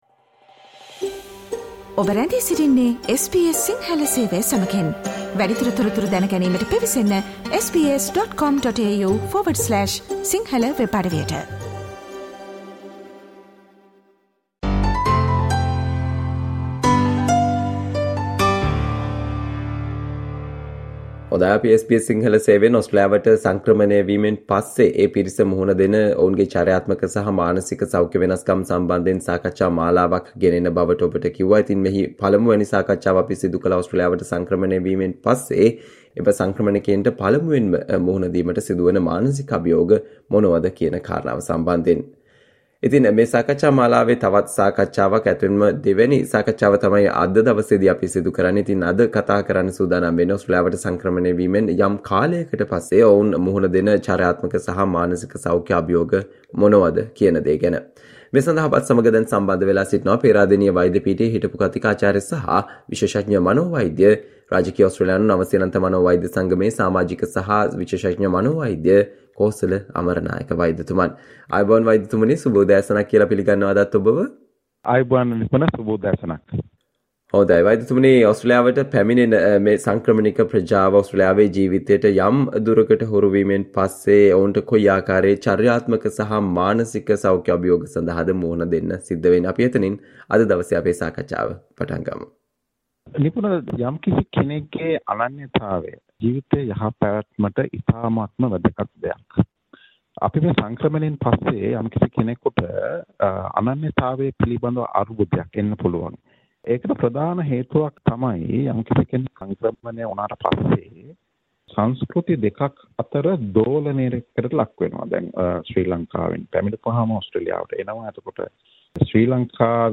SBS Sinhala discussion on Why do family members feel that people who have migrated to Australia have changed?